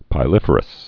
(pī-lĭfər-əs)